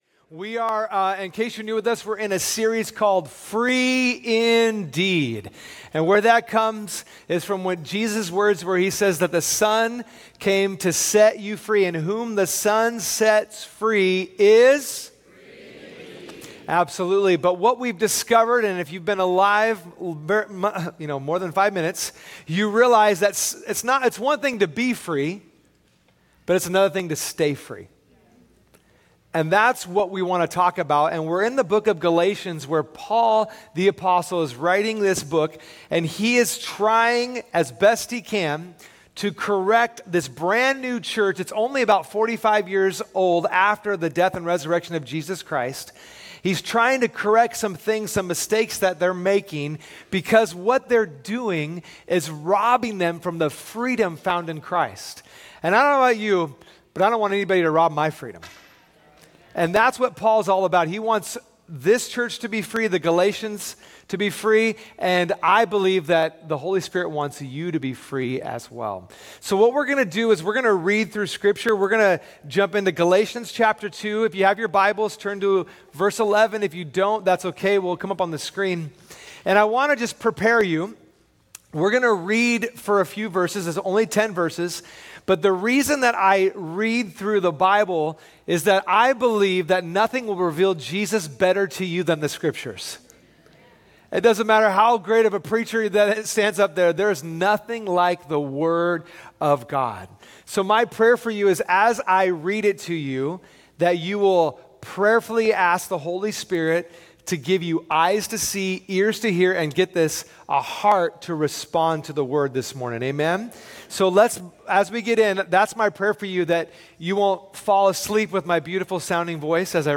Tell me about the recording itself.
Sunday Messages from Portland Christian Center "The Power of Grace" | FREE INDEED | Part 4 Jul 21 2024 | 00:41:00 1x 00:00 / 00:41:00 Subscribe Share Spotify RSS Feed Share Link Embed